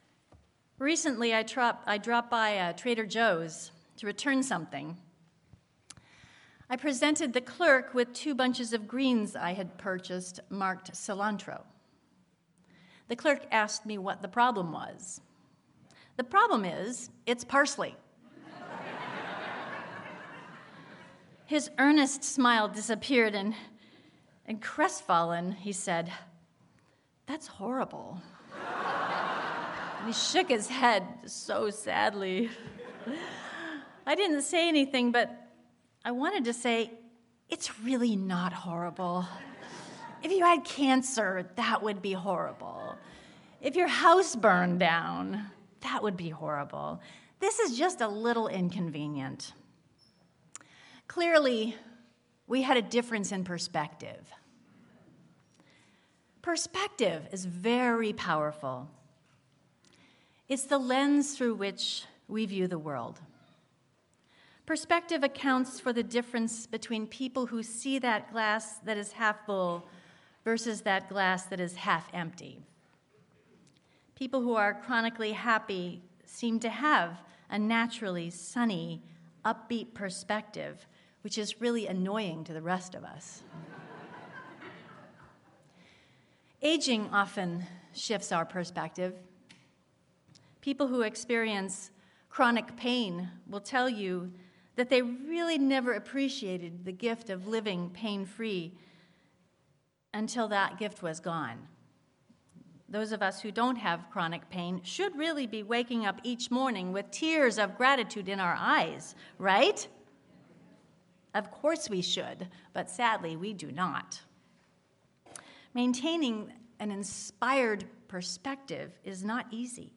Sermon-Focusing-on-What-Matters.mp3